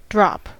drop: Wikimedia Commons US English Pronunciations
En-us-drop.WAV